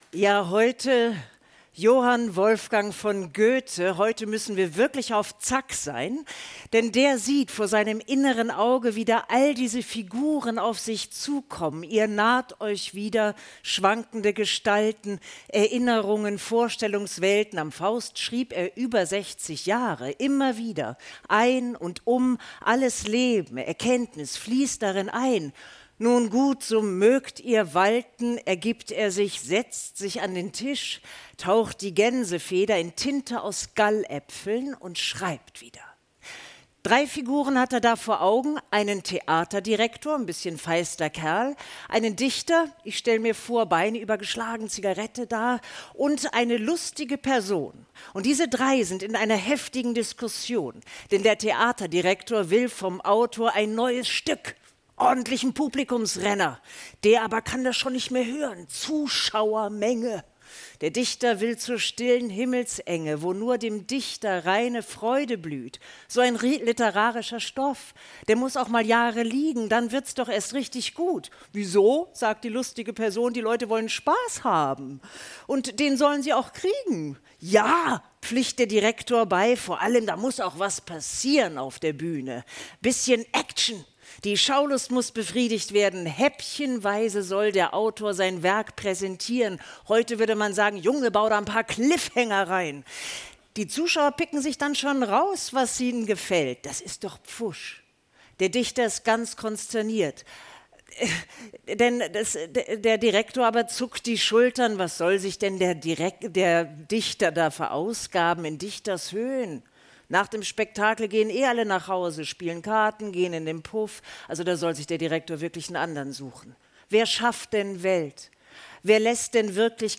Aus dem Studio 14 in der Radiolounge »Richtig gutes Zeug!«